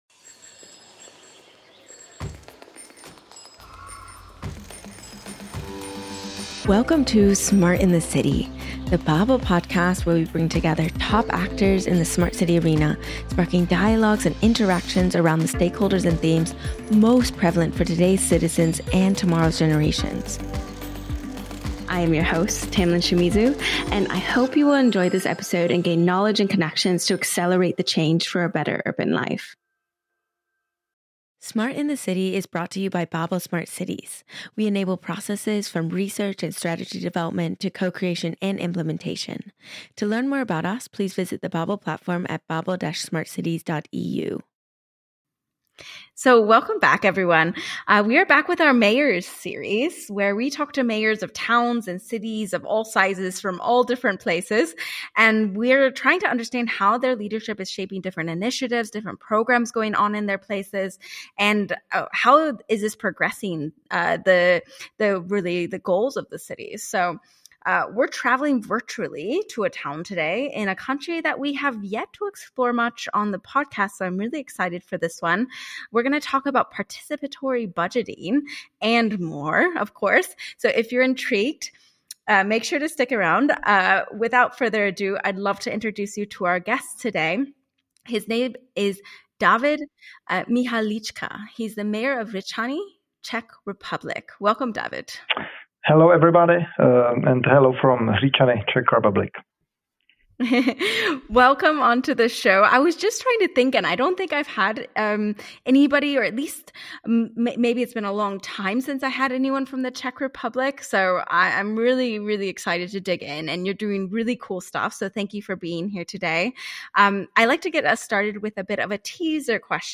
From forest trails to railway lines, this conversation explores how simple, inclusive mechanisms can build smarter, more connected towns.